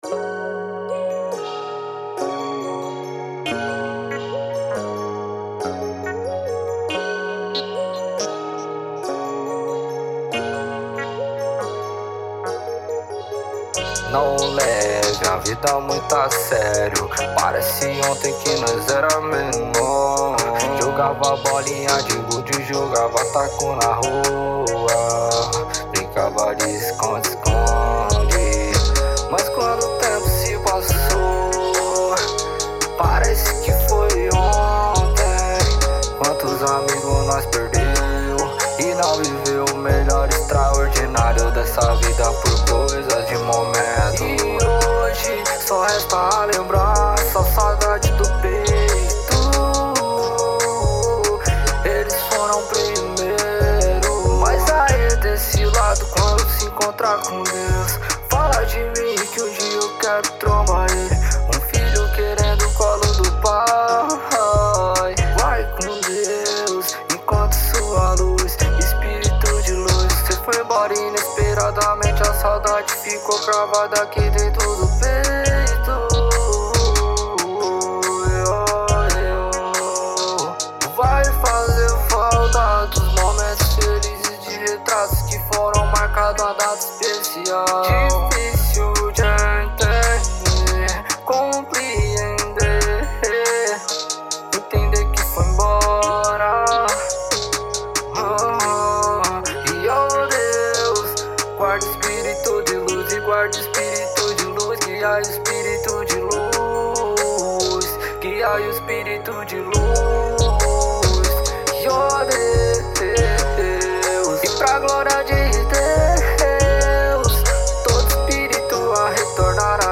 EstiloTrap